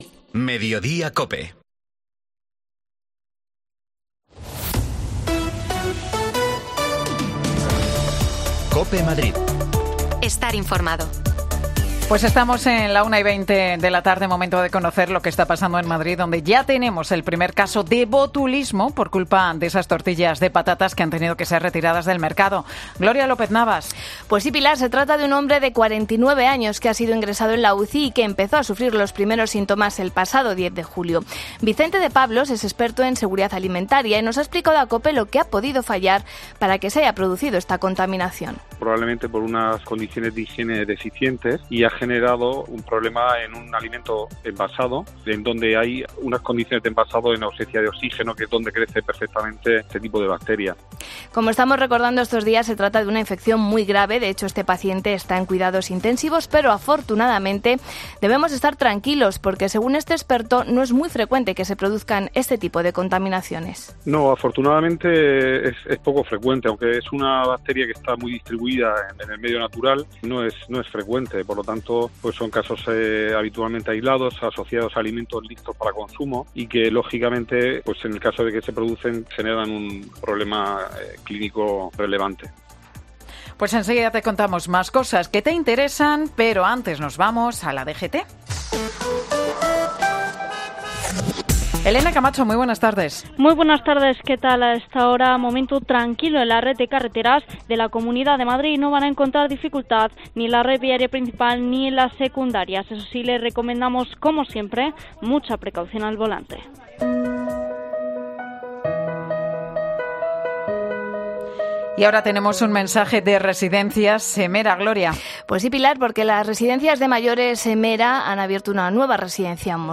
Los expertos nos cuentan cómo se han podido contaminar las tortillas de patatas.